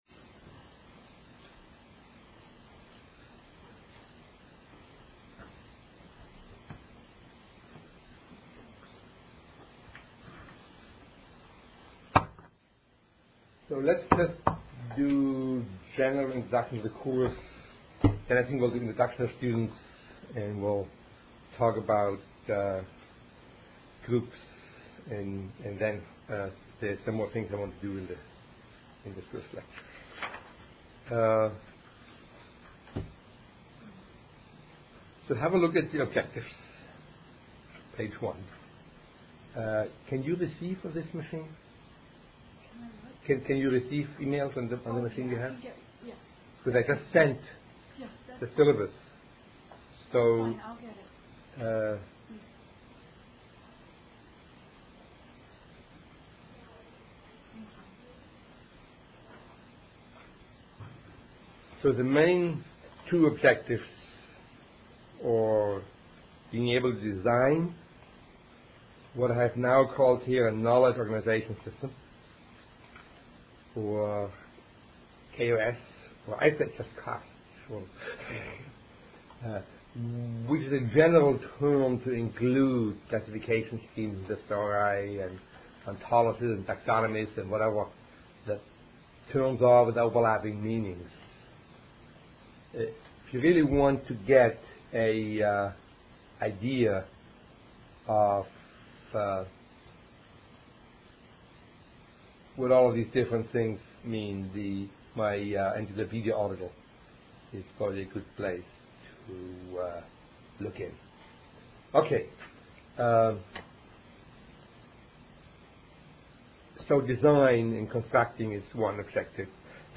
Lecture 1.1